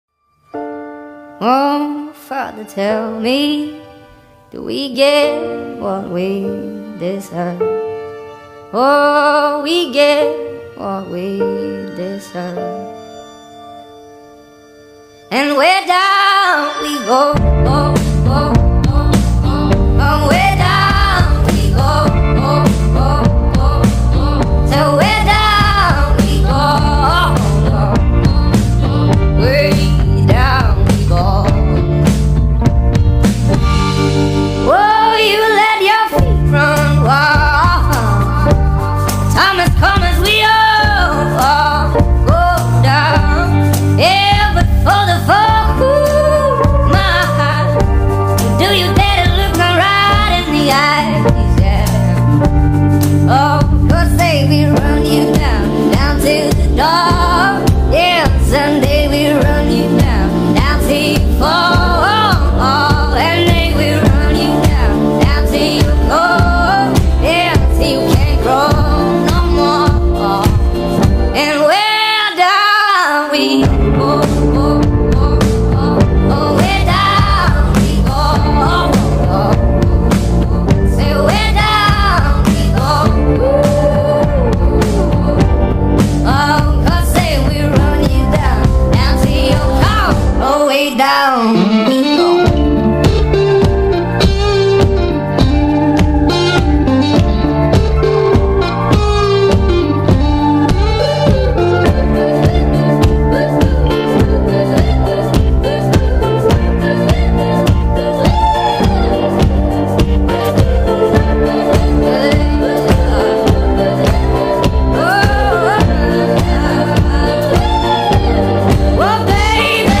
ریمیکس افزایش سرعت صدای بچه